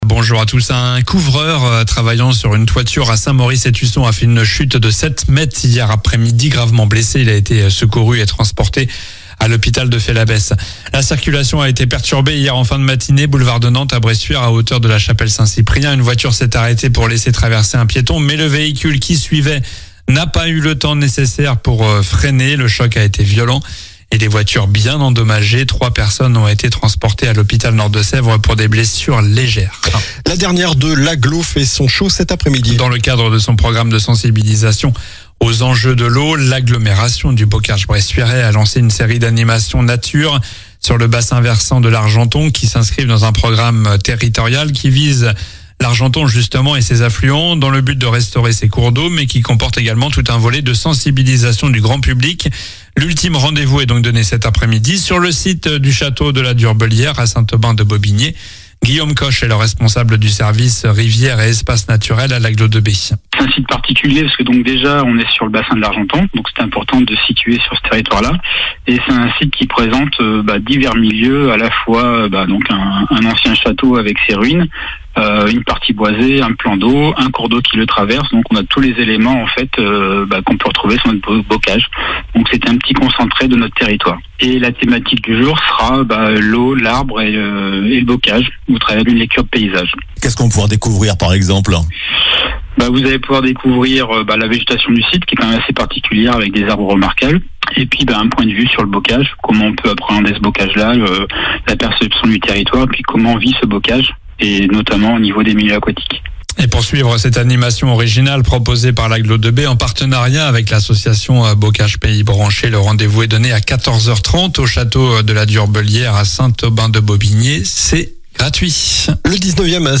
Journal du samedi 15 novembre (matin)